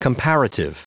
Prononciation du mot comparative en anglais (fichier audio)
Prononciation du mot : comparative